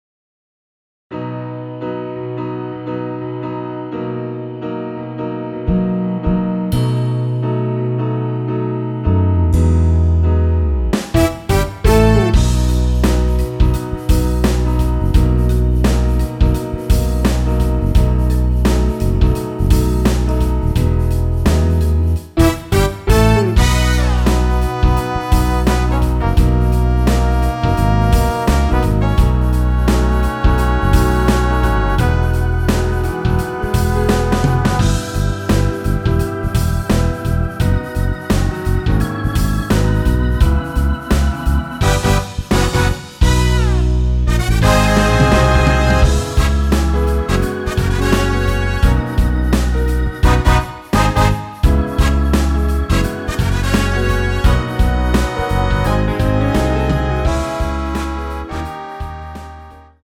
원키에서(-2)내린 MR입니다.
앞부분30초, 뒷부분30초씩 편집해서 올려 드리고 있습니다.
중간에 음이 끈어지고 다시 나오는 이유는